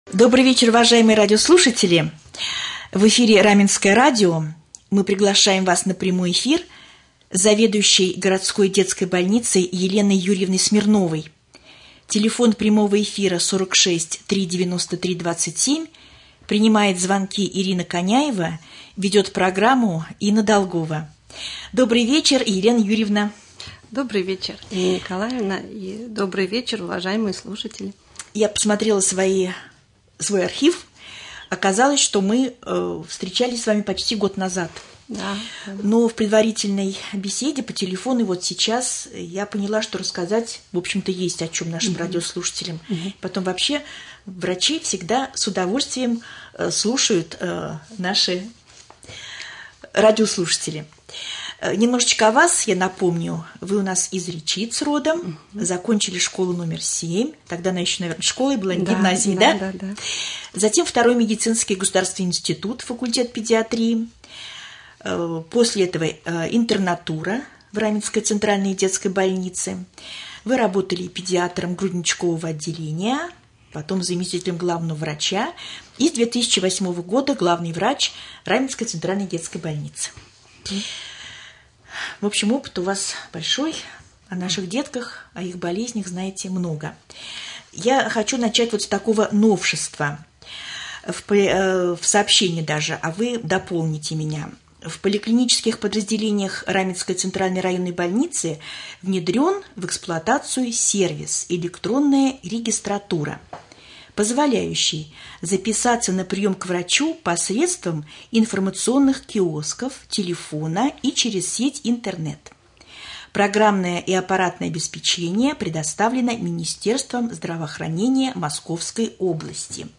Прямой-эфир.mp3